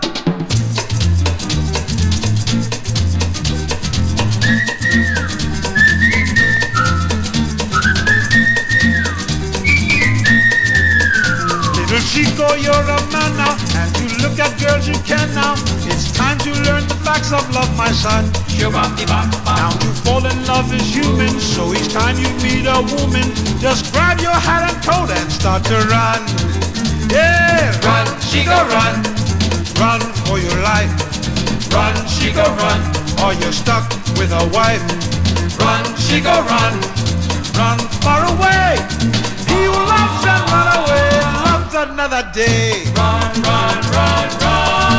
笑みが溢れるコーラス、チキチキドンドンリズムにオルガンに口笛などと軽快に、ラテンビートが心地良い好盤。
Pop, Vocal, Limbo　USA　12inchレコード　33rpm　Mono